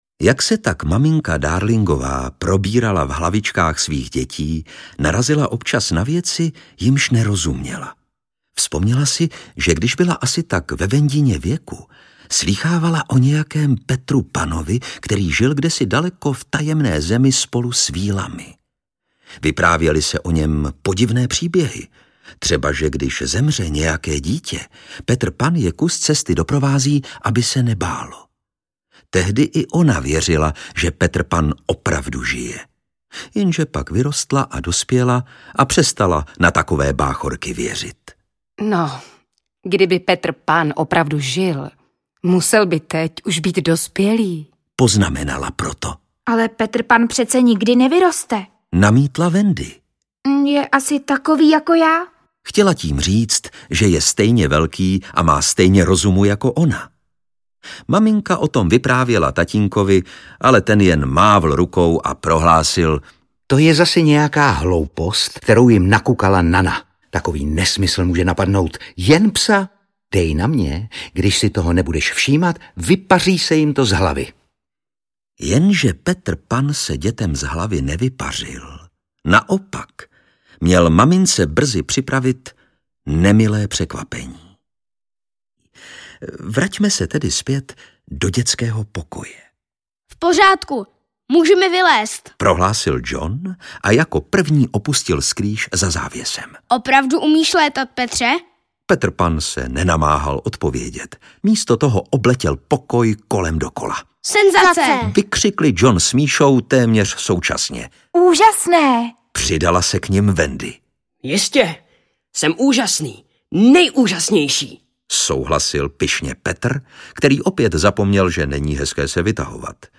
Audio kniha
Ukázka z knihy
Audioknižní dramatizace klasického příběhu o nesmrtelné dětské duši, která dřímá v každém z nás, a o touhách, prodlévajících kdesi na vzdáleném ostrově věčného úsvitu.